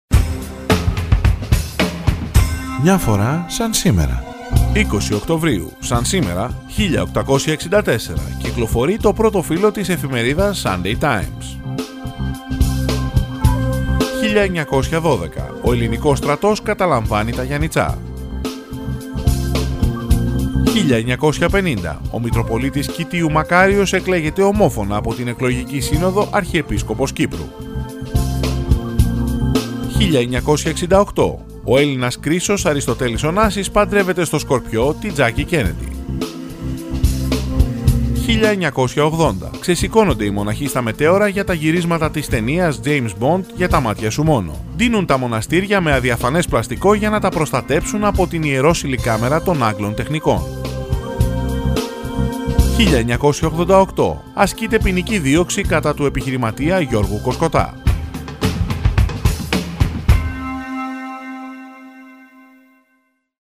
Ανδρική Εκφώνηση – “Μια Φορά… Σαν Σήμερα“